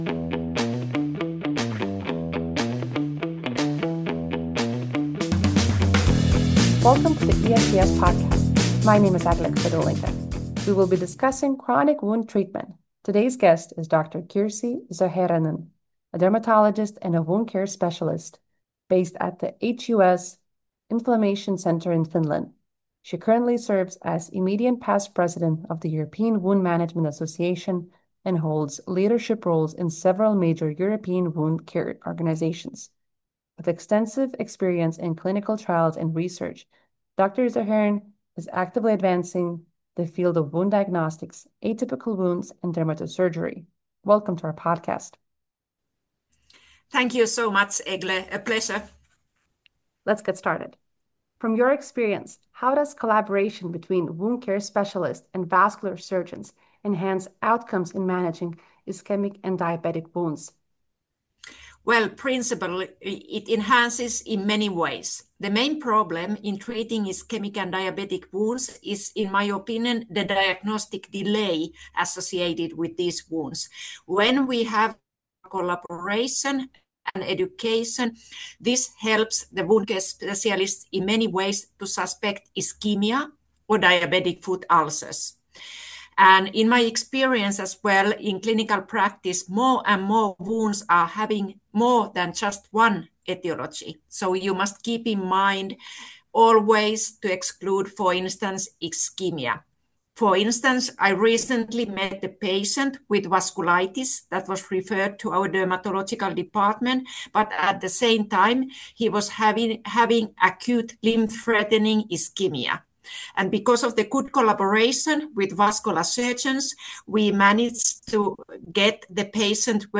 This conversation covers both frontline clinical insights and forward-looking perspectives from one of Europe’s leading wound healing centres.